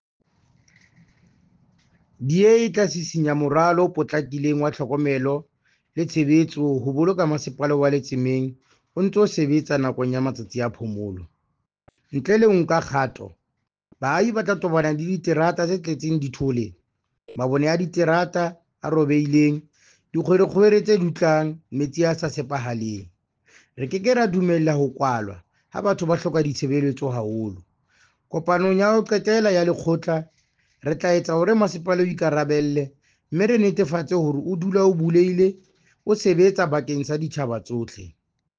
Issued by Cllr. Thabo Nthapo – DA Councillor Letsemeng Municipality
Sesotho soundbites by Cllr Thabo Nthapo.